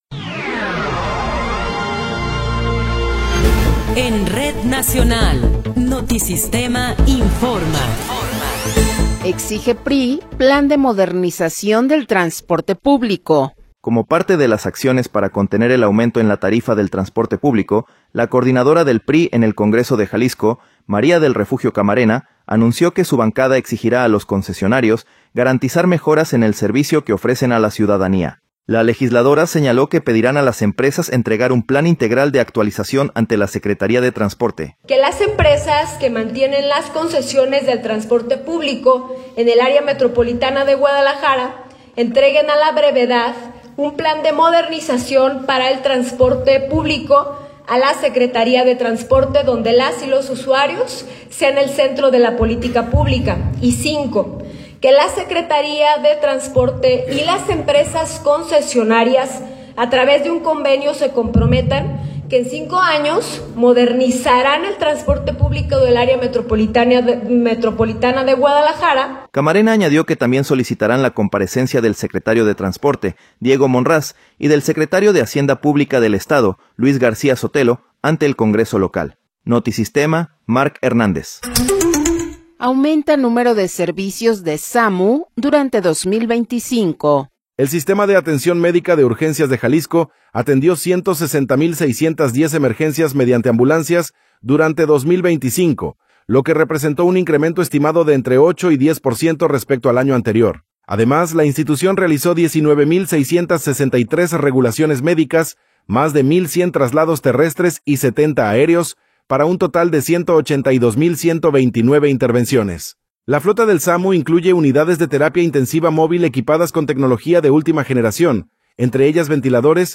Noticiero 15 hrs. – 5 de Enero de 2026